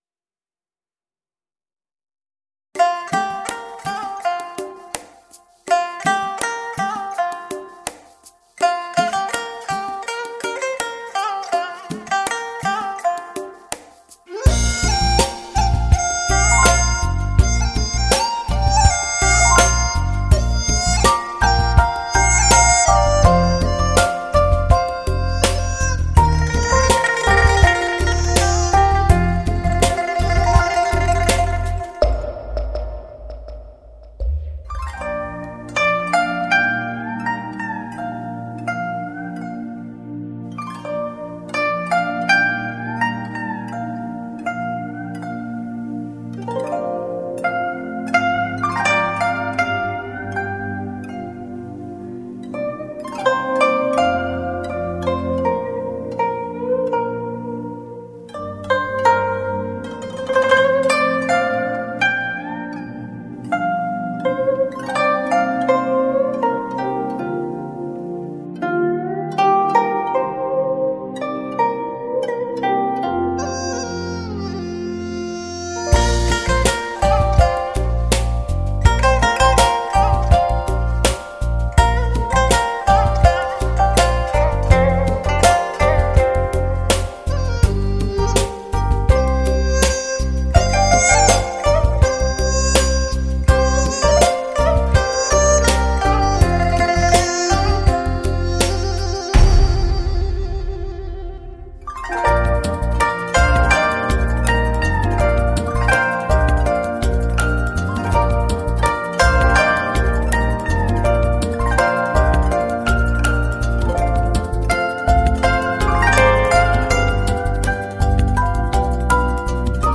古筝演奏